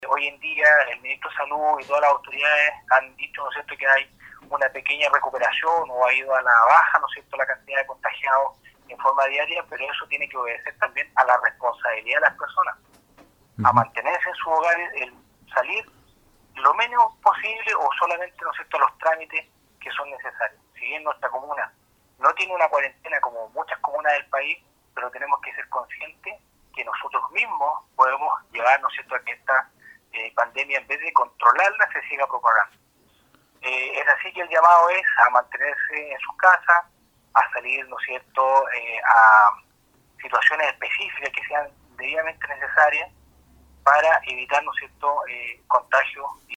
El oficial uniformado reiteró la importancia de una conducta de las personas acorde a la emergencia que está viviendo el país y no propagando acciones que pueden ser perjudiciales para la sociedad.